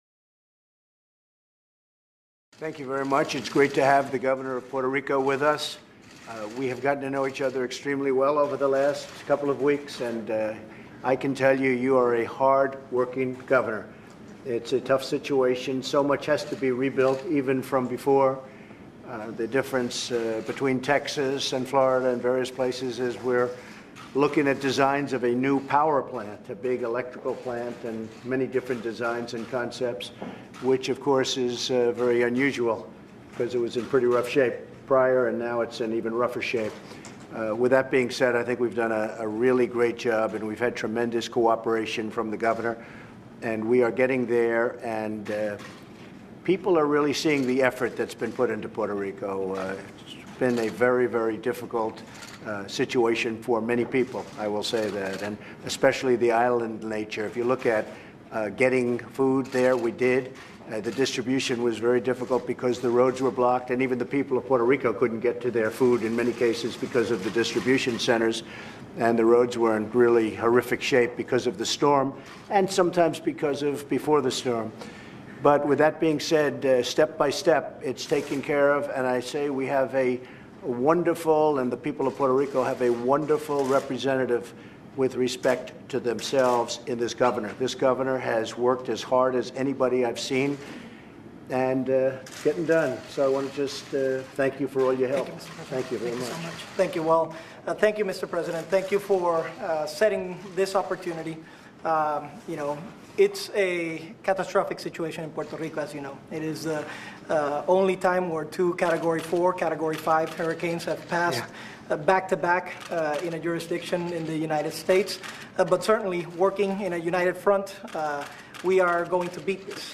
Trump praises the federal government's hurricane response in Puerto Rico and says that that federal aid will continue to be provided to Puerto Rico but that eventually local organizations will need to step up. Rosselló says that there is still much more work to be done and that federal aid is not being abused. Held at the Oval Office.